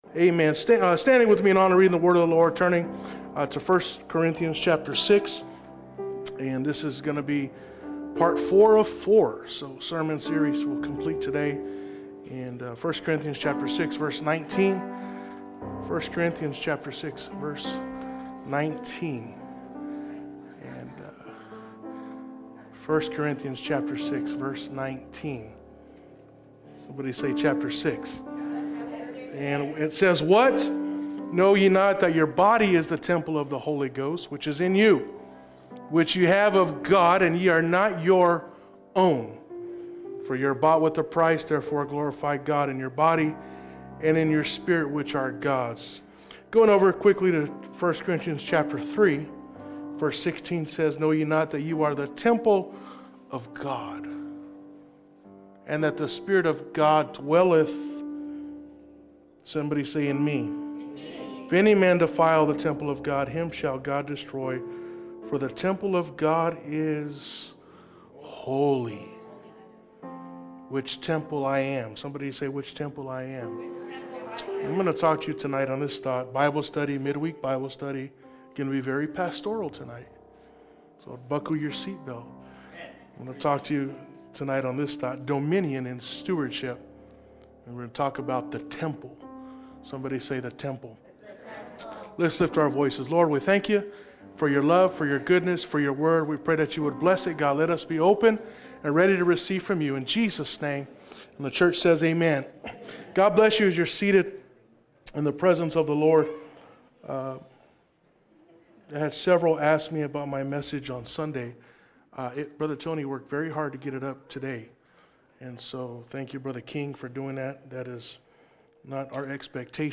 Dominion In Stewardship Service Type: Mid-Week Service Passage